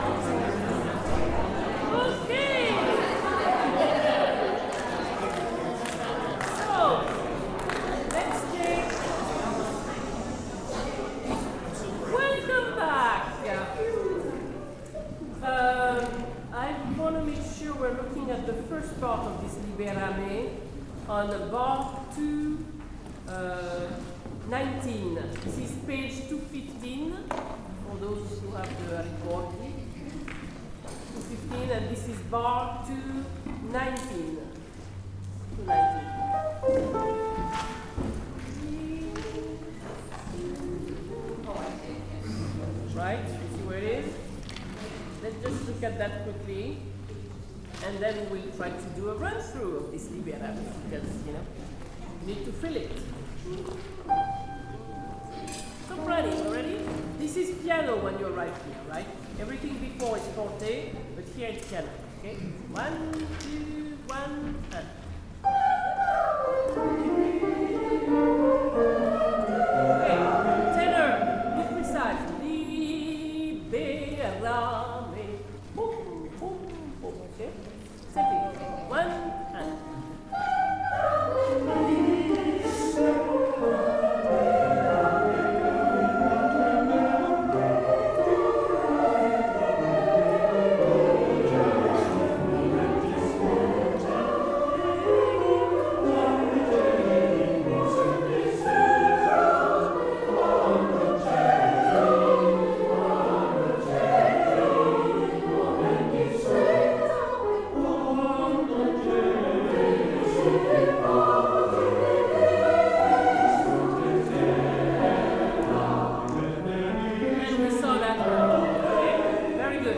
OSC Rehearsal, Wednesday, 17 September 2025